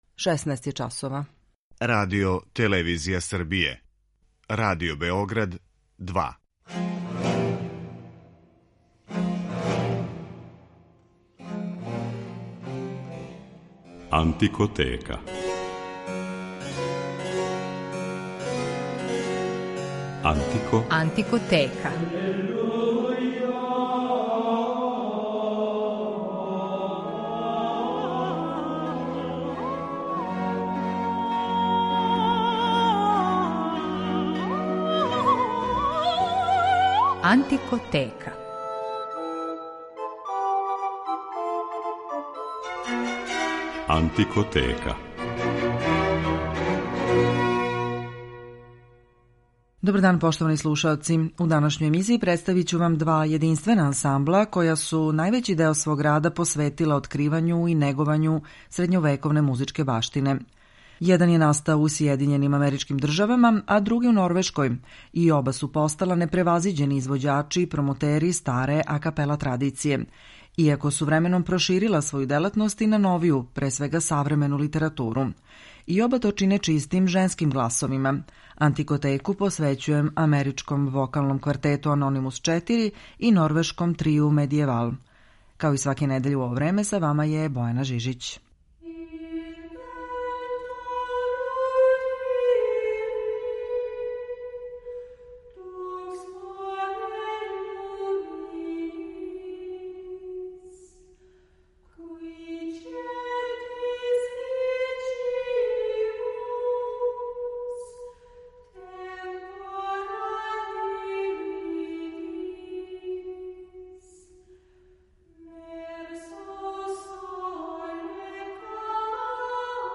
Као саставе анђеоских гласова описују критичари и норвешки Трио „Medieval” и амерички квартет „Anonymus 4”, који је, након тридесет година рада, 2016. одлучио да се заувек опрости од публике.
Овим славним ансамблима женских гласова, који спадају међу најбоље на свету, блиским и по репертоару и по врсти префињеног звука који негују, посвећена је данашња емисија. Слушаћете их у извођењу музике за коју су се, пре свега, специјализовали ‒ духовне и световне вокалне заоставштине средњег века, али и у старој традиционалној музици својих народа. У рубрици „Антикоскоп" говорићемо о једном спектакуларном открићу и партитури коју заједно потписују чувени либретиста Лоренцо да Понте, Моцарт, Салијери и извесни Корнети.